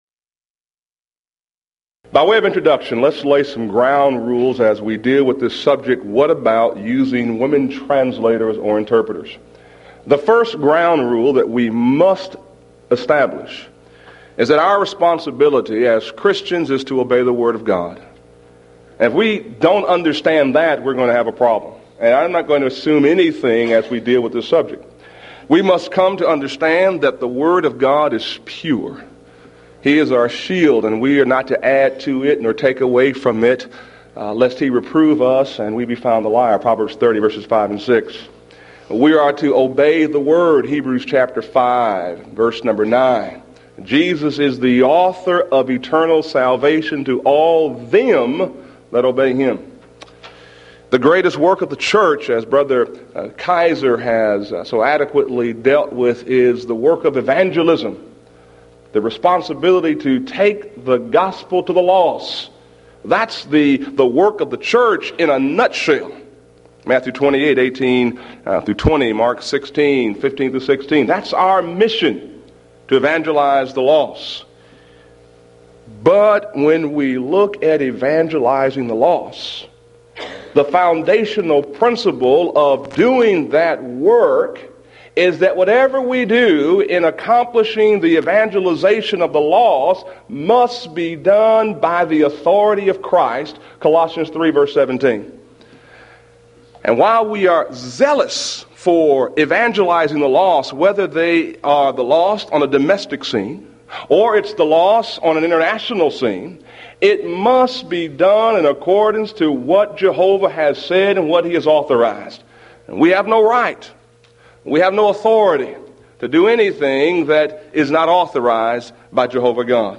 Event: 1994 Mid-West Lectures
lecture